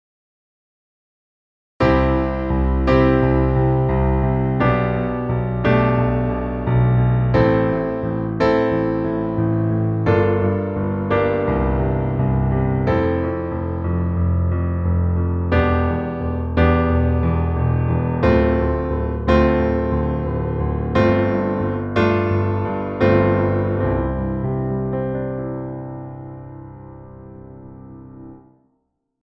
オクターブ演奏
オクターブ演奏.mp3